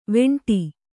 ♪ veṇṭi